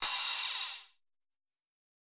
Crashes & Cymbals
Boomin - Cymbal 5.wav